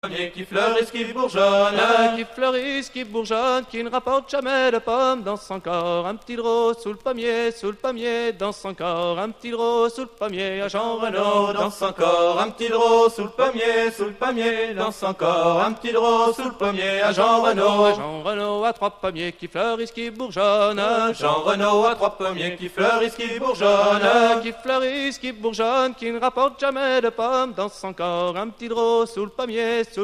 Musique : Traditionnel
Interprètes : Maluzerne, Les Ours du Scorff
Origine : Bretagne
Danse : Laridé
maluzerne - chants et musique a danser - 10-jean renaud (laride).mp3